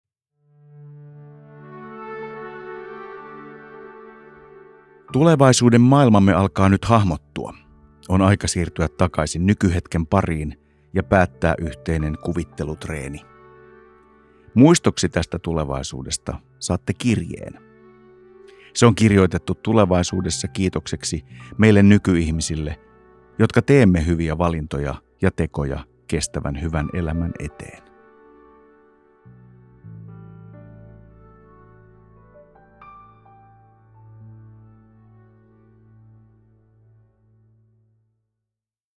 Äänite on helppo tapa kuljettaa harjoitusta ja se sisältää äänitehosteita kuvittelun helpottamiseksi ja sopivan tunnelman luomiseksi.